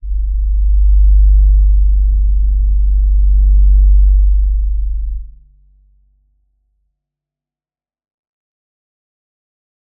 G_Crystal-G1-f.wav